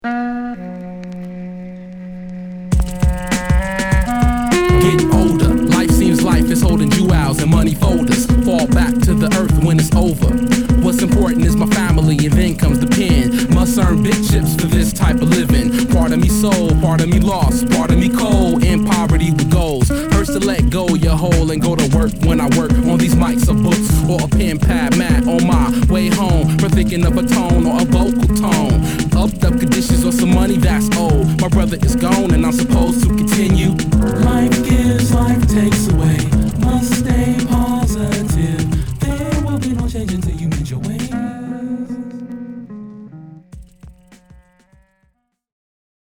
ホーム HIP HOP UNDERGROUND 12' & LP S